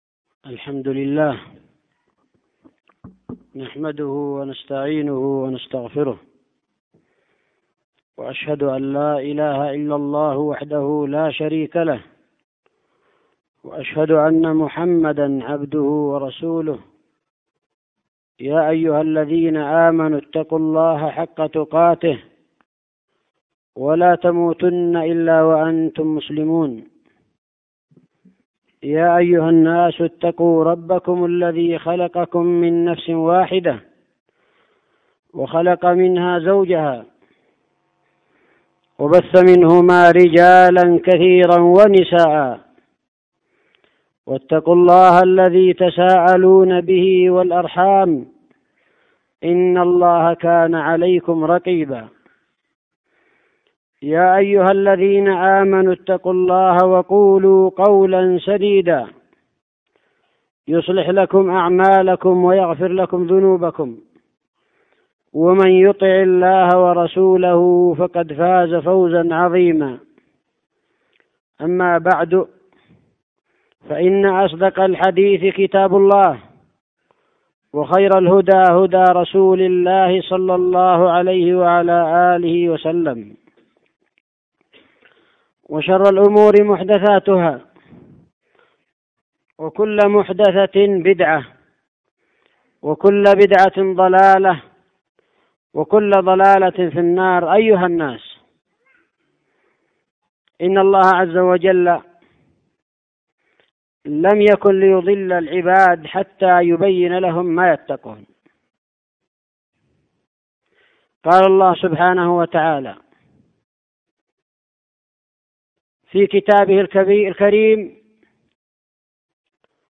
خطبة جمعة بعنوان: (( وضوح الدليل على بيان السبيل))